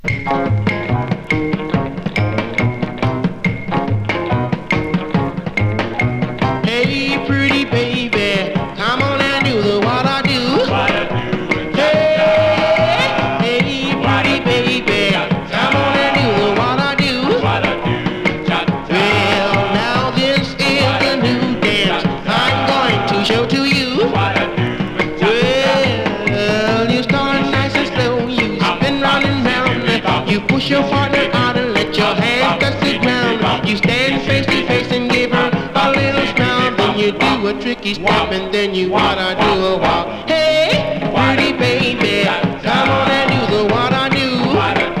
Rock’N’Roll, Doo Wop　USA　12inchレコード　33rpm　Mono